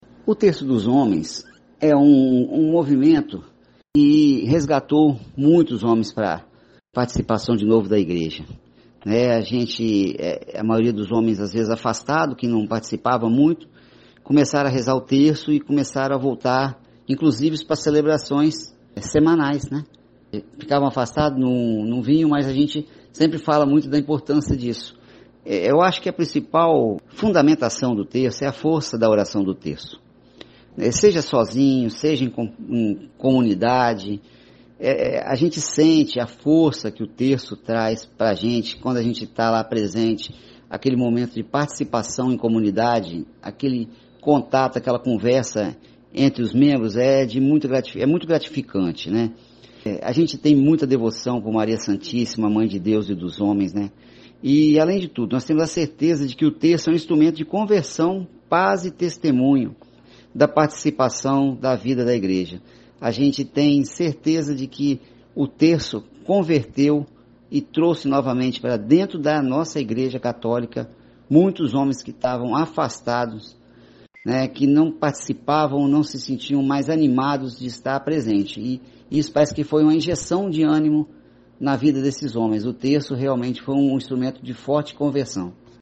Pela primeira vez, adotamos o formato de entrevista com respostas em áudio (mp3).